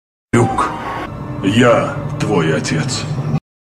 Звук памятной речи Темного Лорда